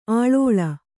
♪ aḷōḷa